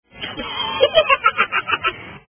Lachen
laugh.wav